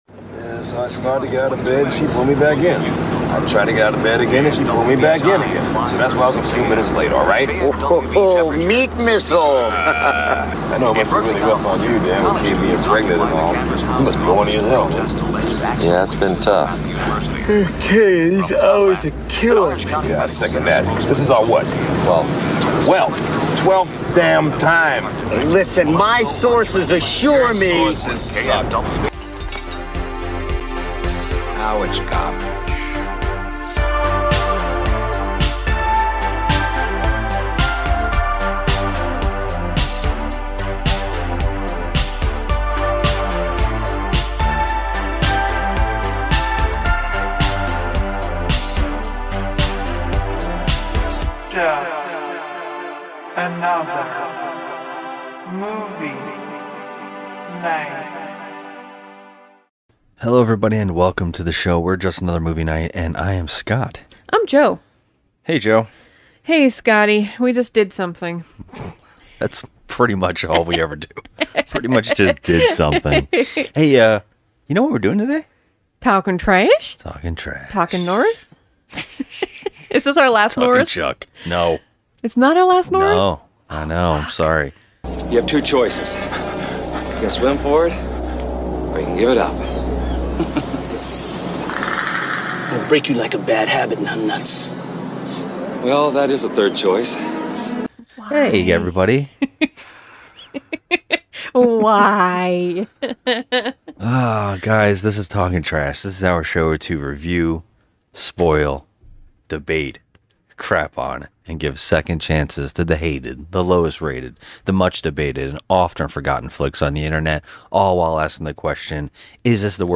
Finally we decided to get off our lazy Asses and start recording our nightly rants, reviews and conversations on all things entertainment (but mainly horror movies).